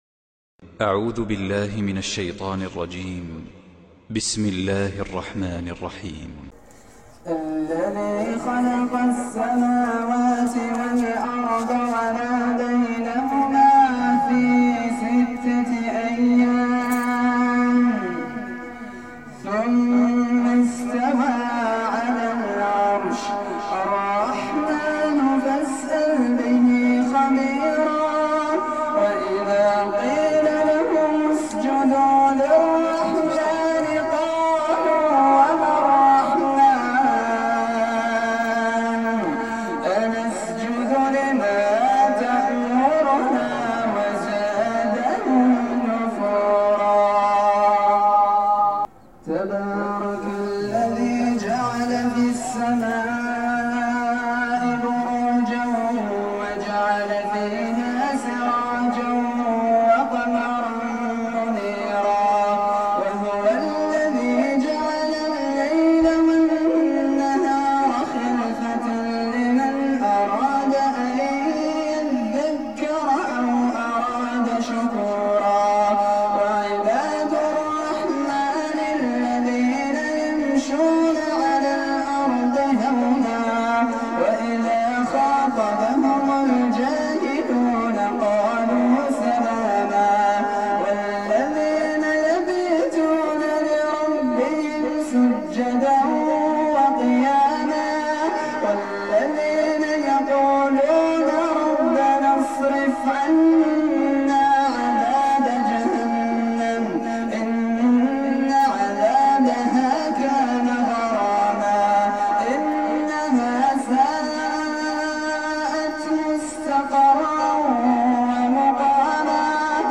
تلاوة في قمة الخشوع